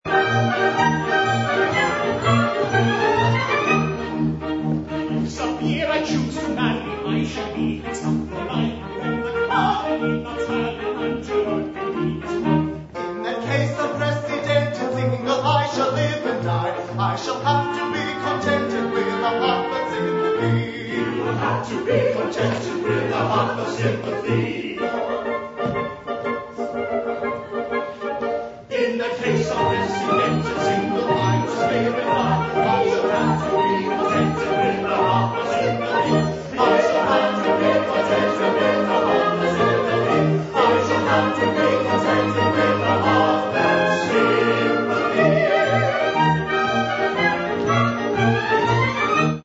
making live recordings of the society's productions.